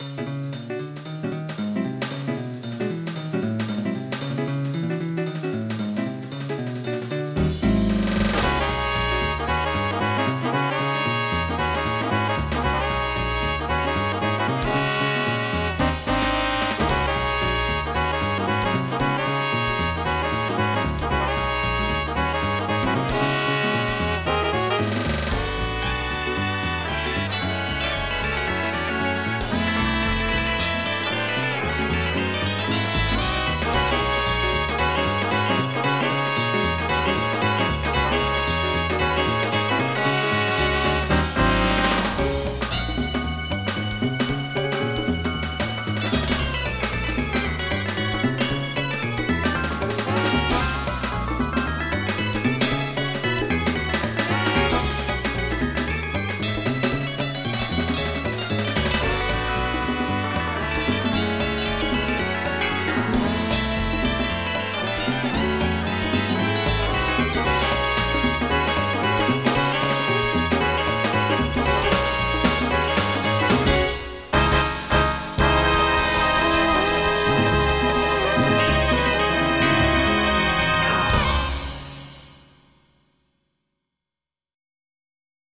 Jazz / DEADDEND.MID
MIDI Music File